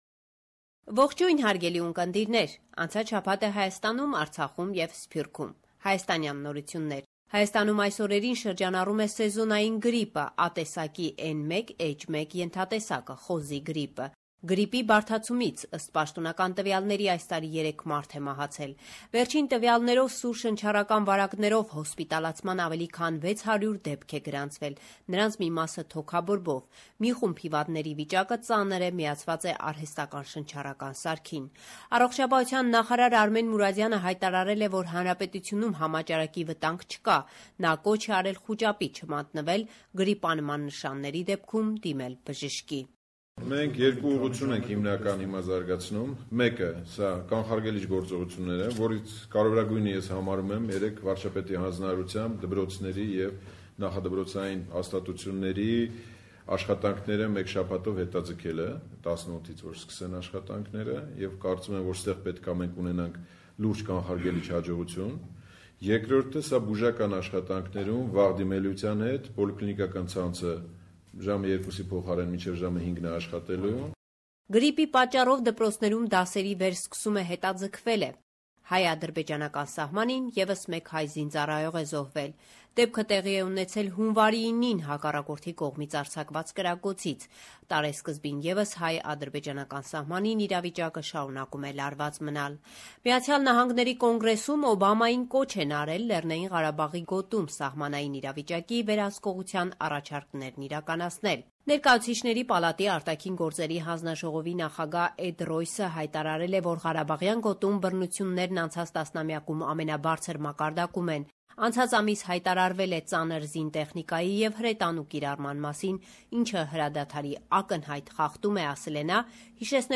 Latest news 12/1/2016